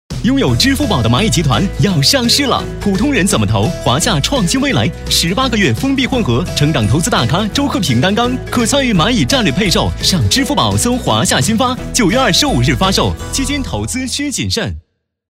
样音试听 - 红樱桃配音-真咖配音-500+真人配音老师 | 宣传片汇报纪录动画英文粤语配音首选平台